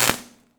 zap.wav